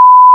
Bleep Sound Effect Free Download
Bleep